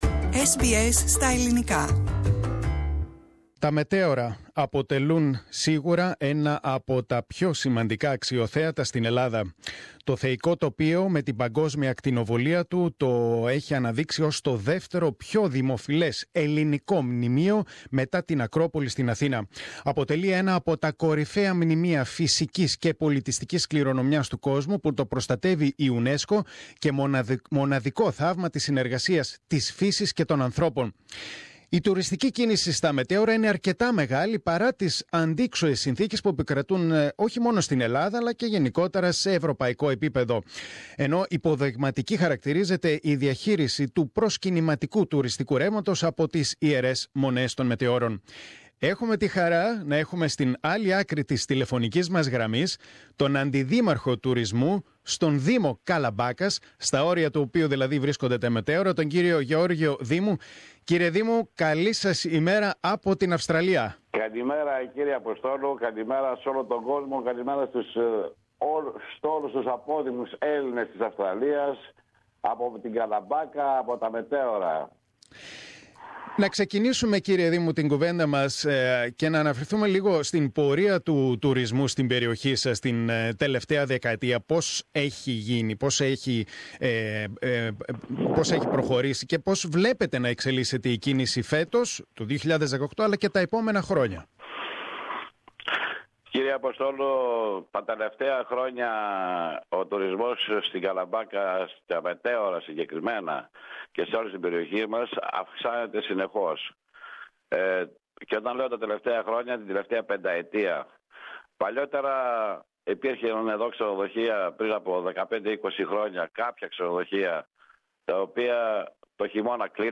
Τα Μετέωρα, αποτελούν σίγουρα ένα από τα πιο σημαντικά αξιοθέατα στη χώρα και ένα απο τα πιο δημοφιλή ελληνικά μνημεία. Στο Πρόγραμμά μας, μίλησε ο Αντιδήμαρχος Τουρισμού του Δήμου Καλαμπάκας, Γιώργος Δήμου.